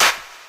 JJClap (3).wav